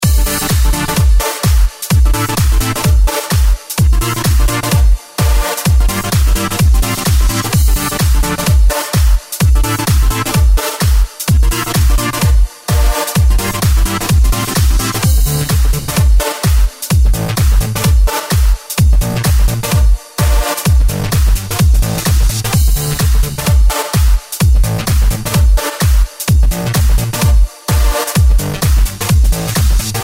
Категория: Клубные рингтоны